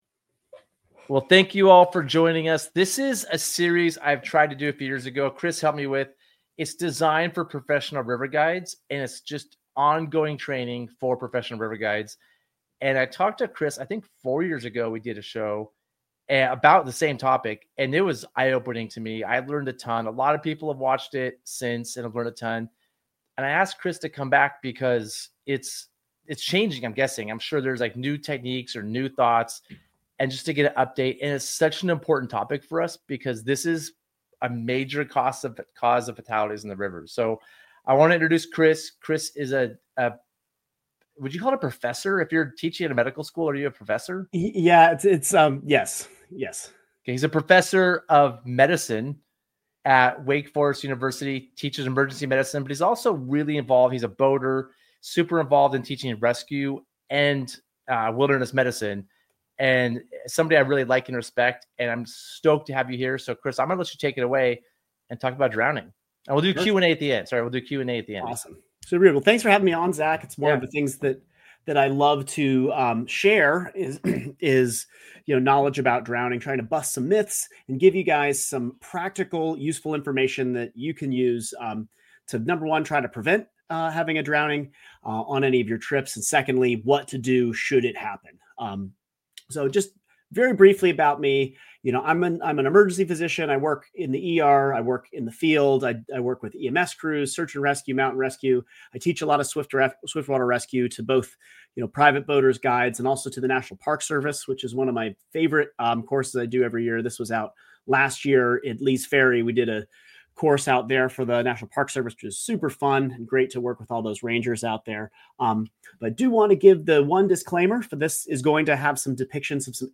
The River Guide Seminar Series is a video series featuring conversations with seasoned river professionals, aimed at continuing education for professional working river guides. From whitewater safety and rigging tips to leadership, river history, and guiding philosophy, we dive into the knowledge that makes great guides even better.